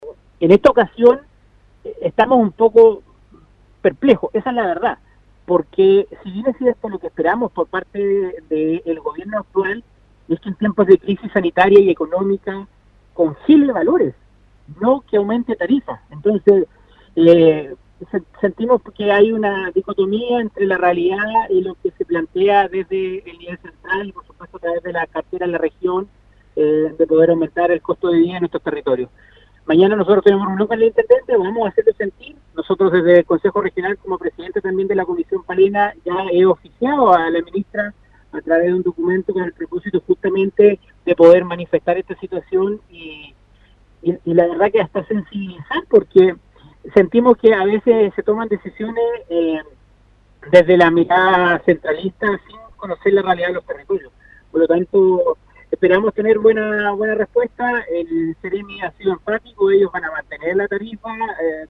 Dijo Roberto Soto estar muy impactado por la información, dado que a causa de la pandemia, estamos viviendo una situación económica excepcional, por lo que no debiera haber este tipo de aumentos en los precios de los transbordos.